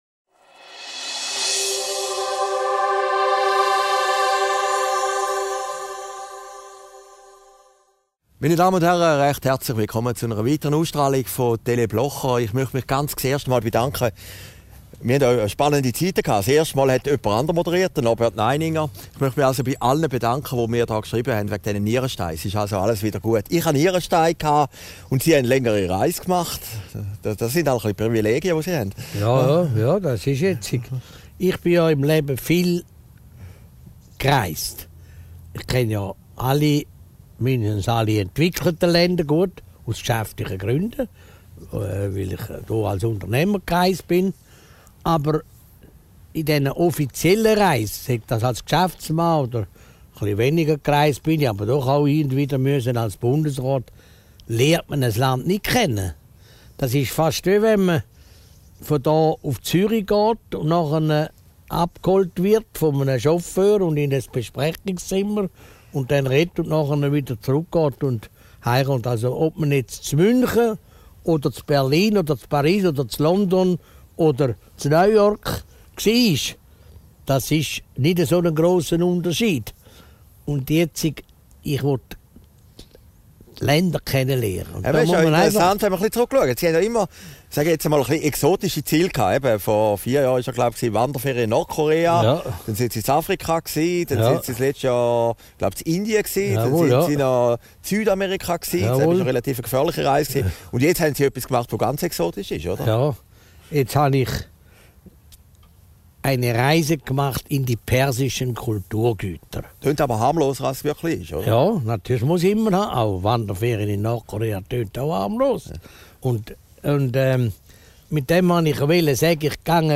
Aufgezeichnet in Herrliberg, am 26. September 2014